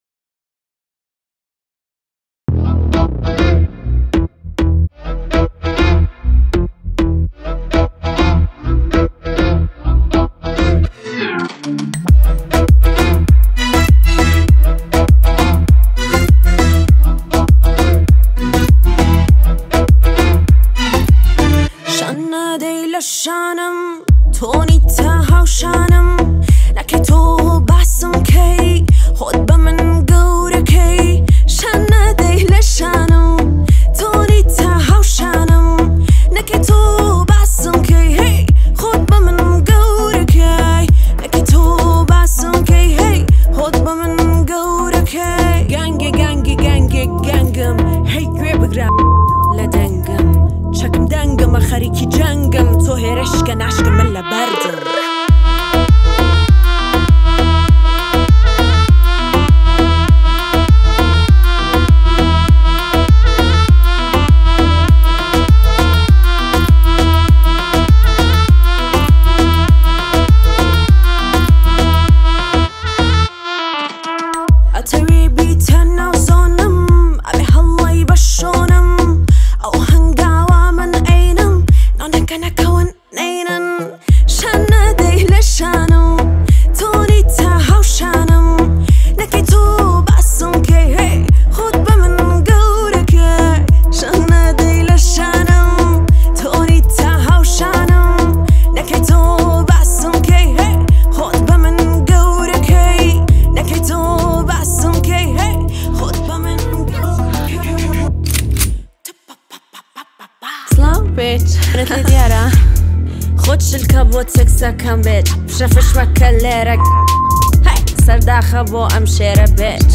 آهنگ کوردی
آهنگ با صدای زن